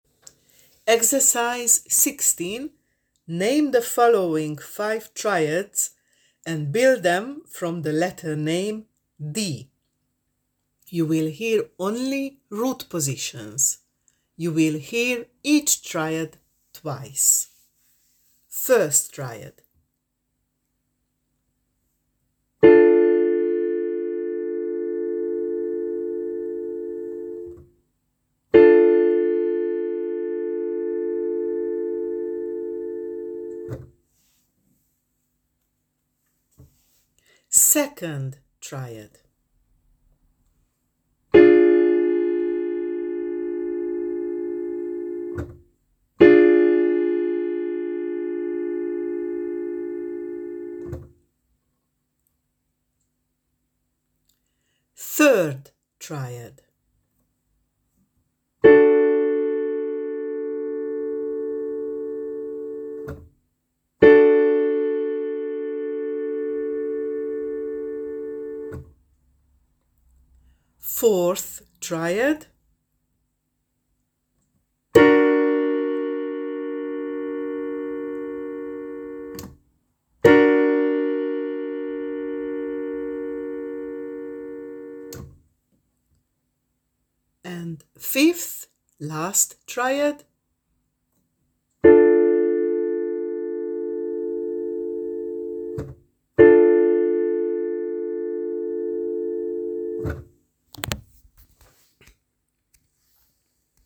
16.Name the following 5 triads and build them from the letter name 'D: You will hear only root positions. You will hear each triad twice: